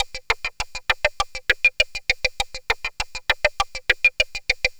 Index of /90_sSampleCDs/Transmission-X/Percussive Loops
tx_perc_100_oddblock2.wav